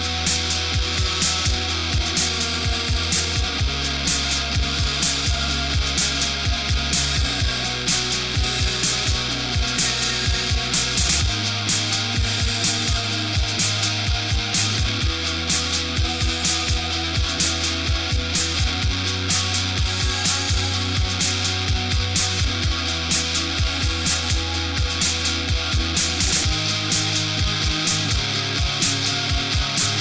O shoegaze é o alucinógeno mais regular